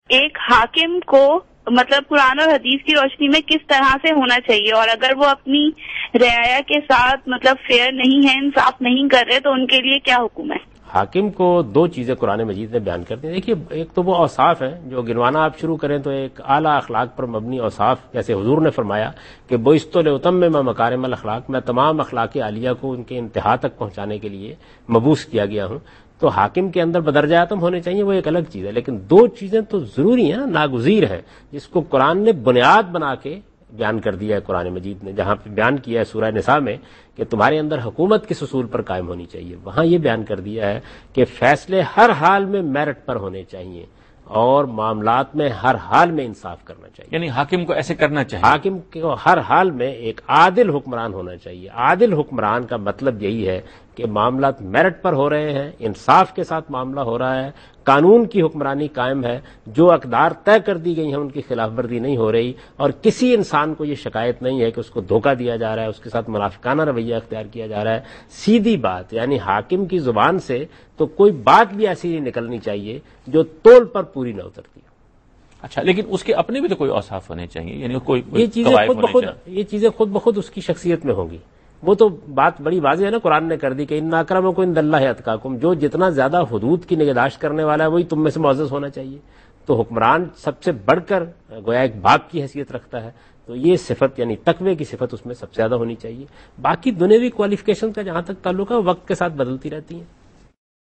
Javed Ahmad Ghamidi answers a question regarding "Obedience of a Ruler" in program Deen o Daanish on Dunya News.
جاوید احمد غامدی دنیا نیوز کے پروگرام دین و دانش میں حکمران کی اطاعت سے متعلق ایک سوال کے جواب دے رہے ہیں۔